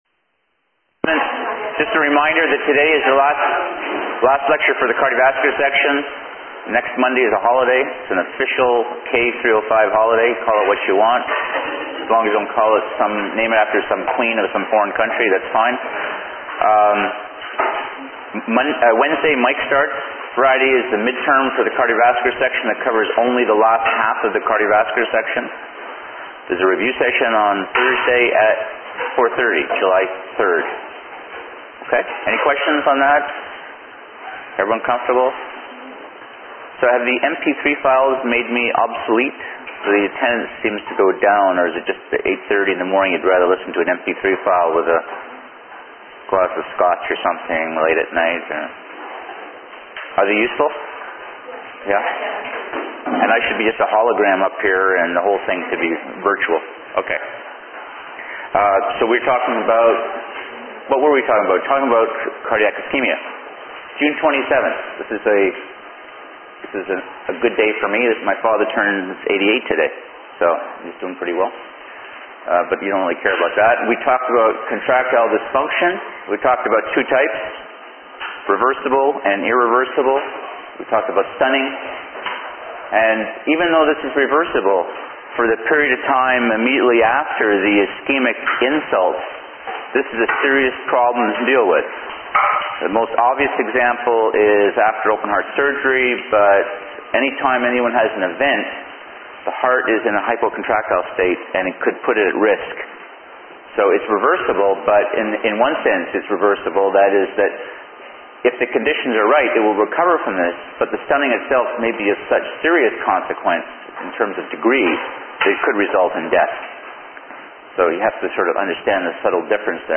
Digital recording of lecture MP3 file requires an MP3 player